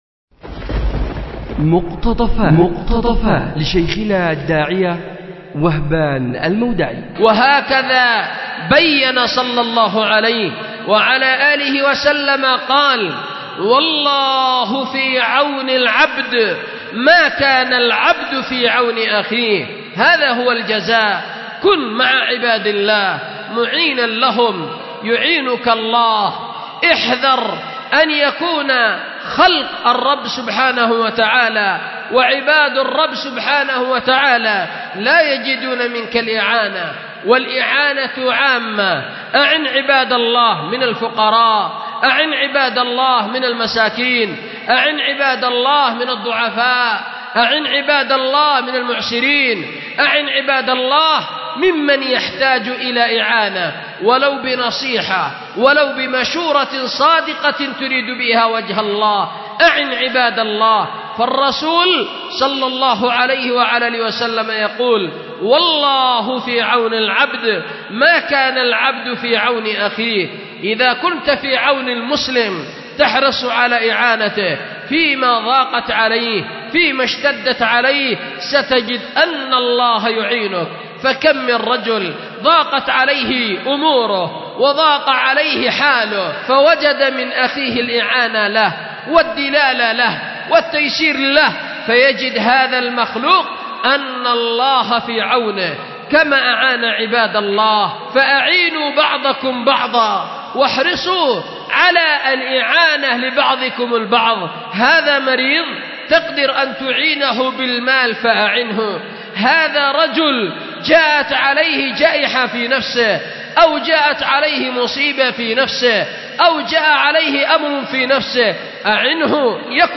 مقتطف لشيخنا الداعية
أُلقيت بدار الحديث للعلوم الشرعية بمسجد ذي النورين ـ اليمن ـ ذمار